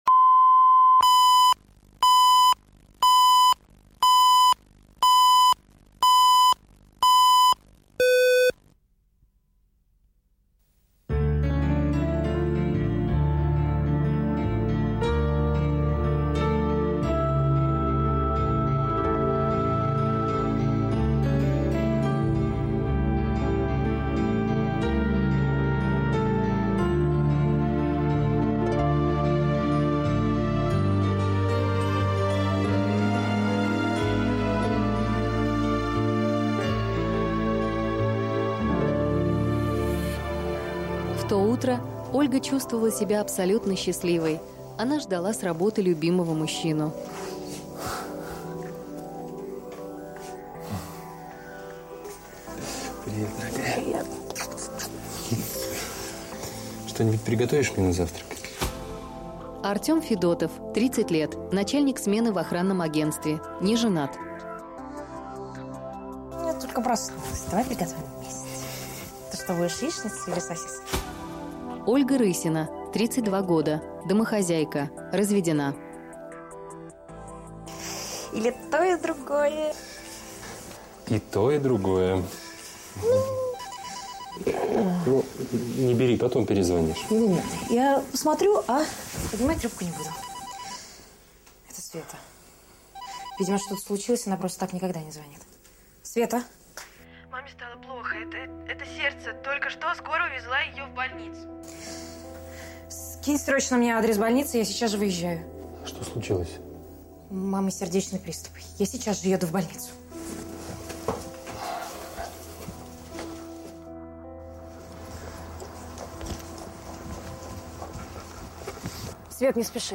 Аудиокнига Обретение матери | Библиотека аудиокниг
Прослушать и бесплатно скачать фрагмент аудиокниги